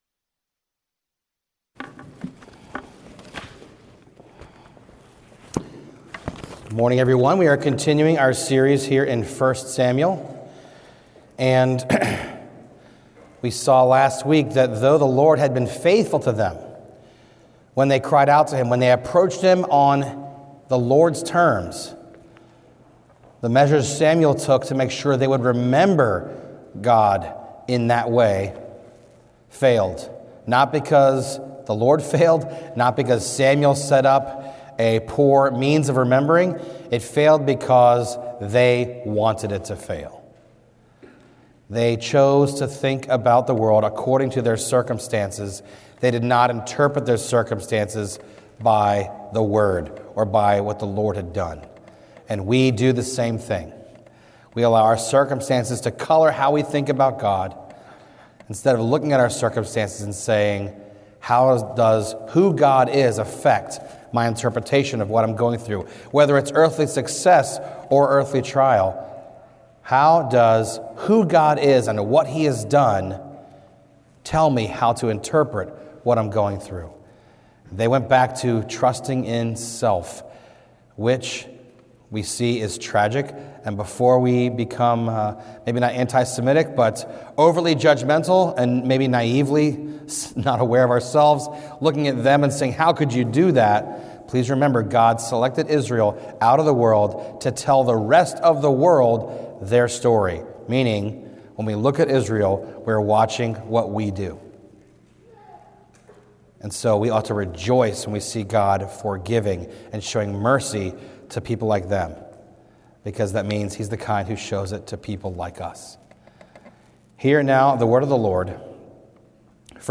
A message from the series "1 Samuel."